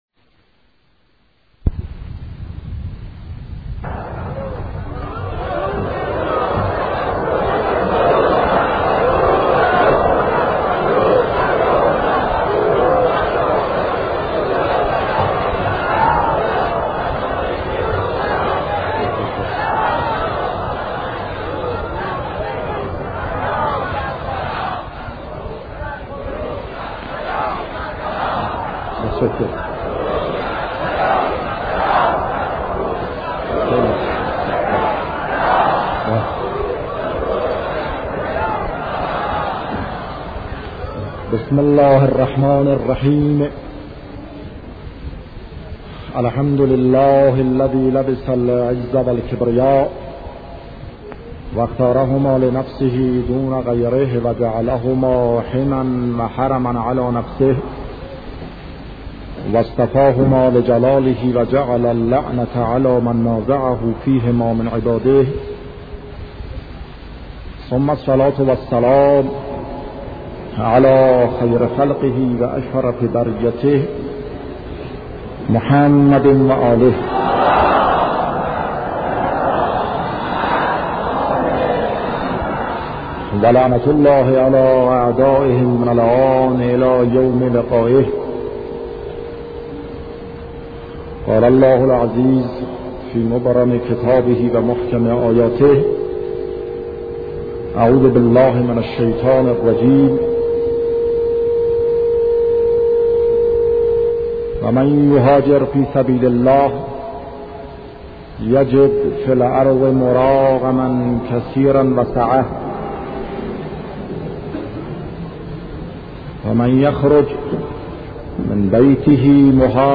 سخنرانی شهید باهنر در سالگرد متفکر، نویسنده و اسلام شناس، مرحوم معلم بزرگ، دکتر شریعتی